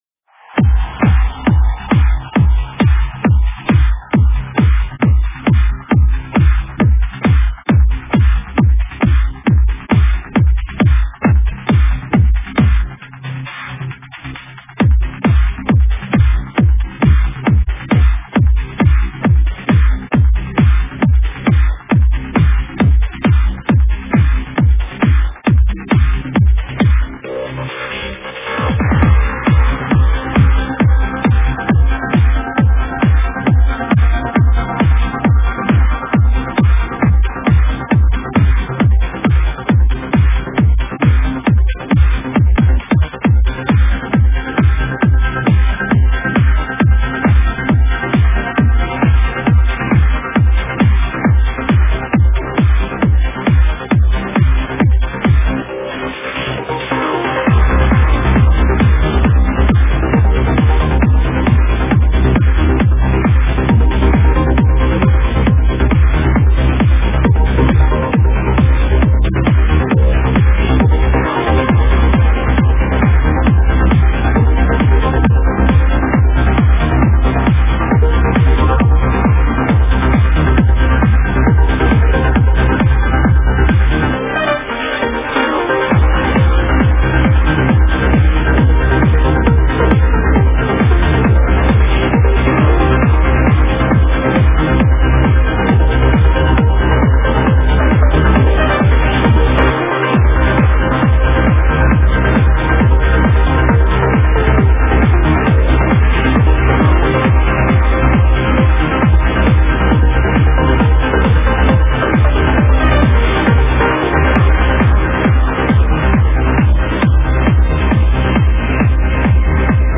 Файл в обменнике2 Myзыкa->DJ's, транс
Жанр: Trance